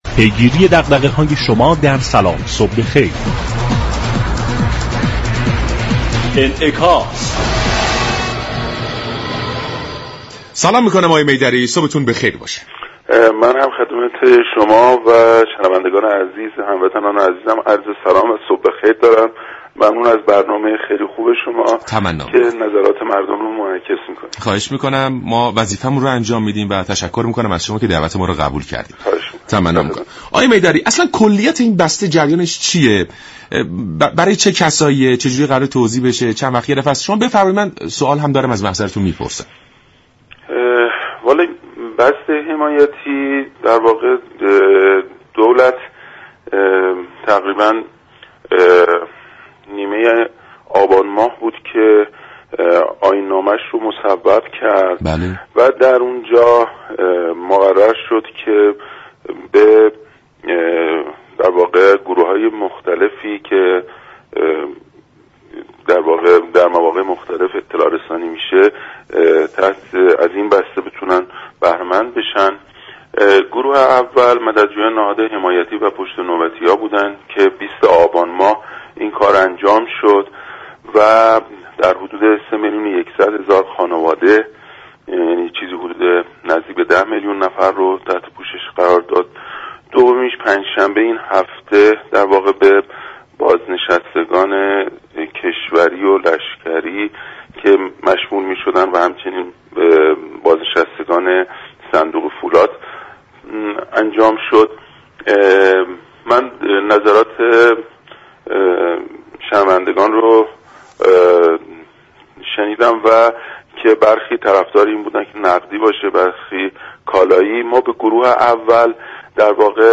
به گزارش شبكه رادیویی ایران؛ میدری معاون رفاه اجتماعی وزارت تعاون، كار و رفاه اجتماعی در گفت و گو با برنامه «سلام صبح بخیر» به جزئیات بسته حمایتی دولت و نحوه توزیع آن پرداخت و در این باره گفت: دولت در آبان ماه سال جاری با تنظیم آیین نامه ای دستگاه ها را موظف كرد در فاصله زمانی مختلف و به نوبت به گروه و قشرهای مردم بسته های حمایتی ارائه دهد.